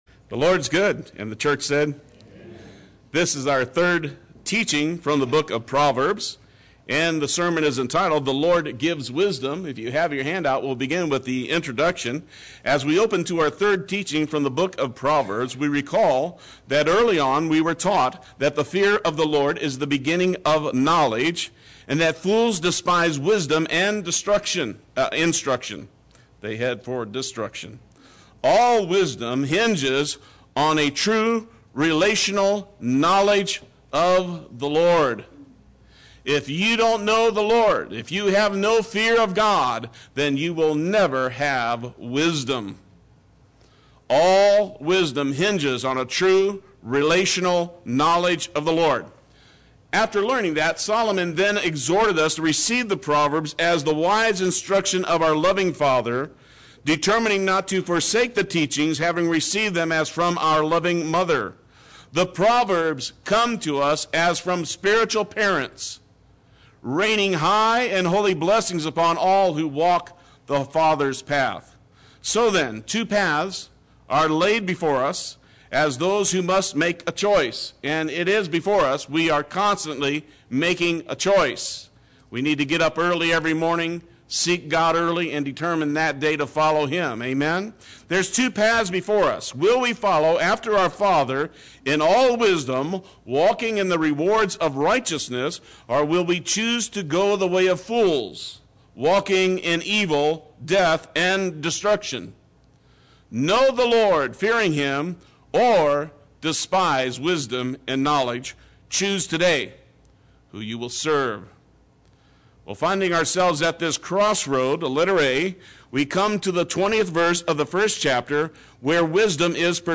Play Sermon Get HCF Teaching Automatically.
The Lord Gives Wisdom Sunday Worship